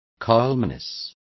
Also find out how apacibilidades is pronounced correctly.